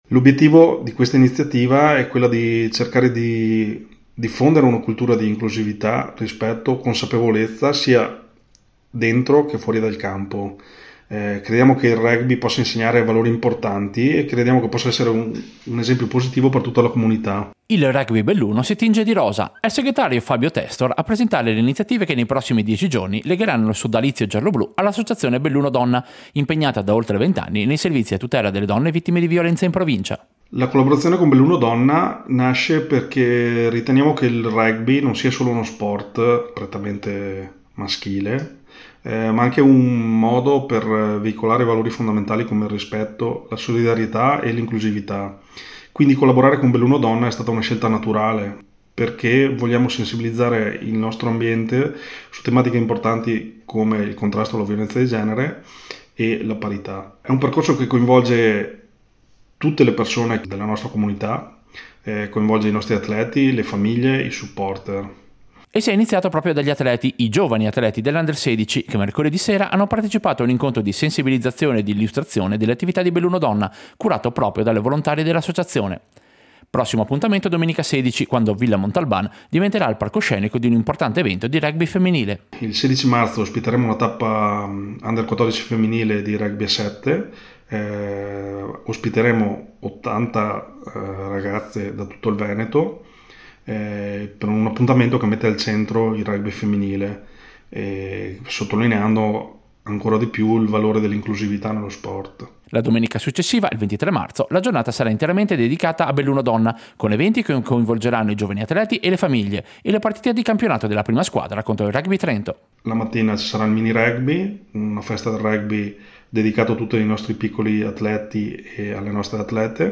Servizio-Rugby-Belluno-e-Belluno-Donna.mp3